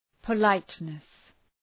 {pə’laıtnıs}